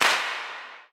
Fireworks Clap.wav